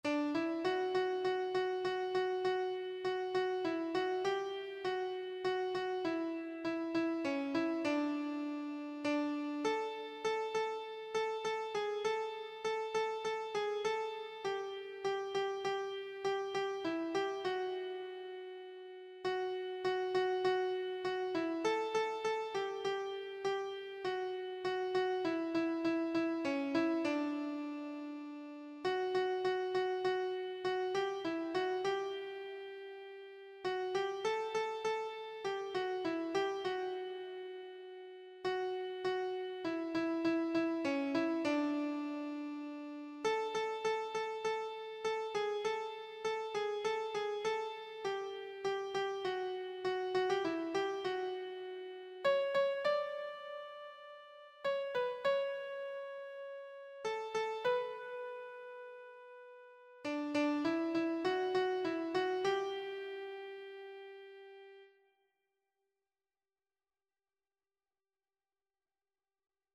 Free Sheet music for Keyboard (Melody and Chords)
D major (Sounding Pitch) (View more D major Music for Keyboard )
4/4 (View more 4/4 Music)
Keyboard  (View more Intermediate Keyboard Music)
Traditional (View more Traditional Keyboard Music)